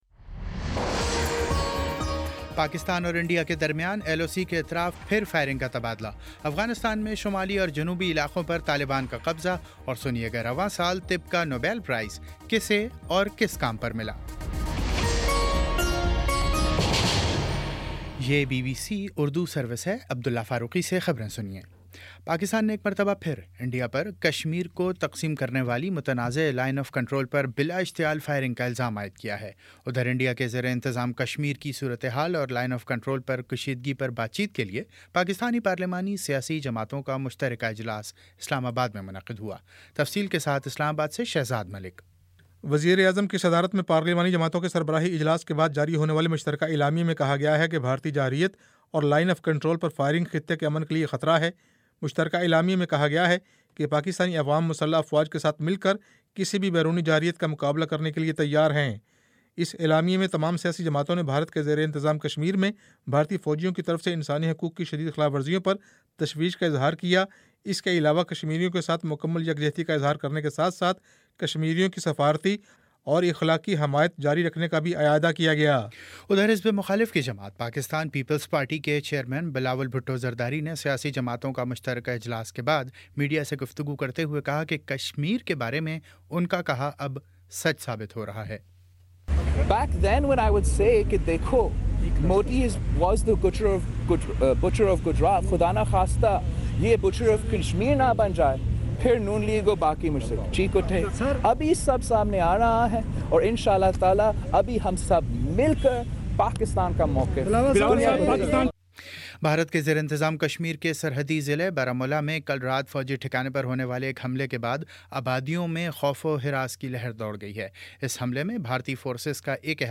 اکتوبر 03 : شام پانچ بجے کا نیوز بُلیٹن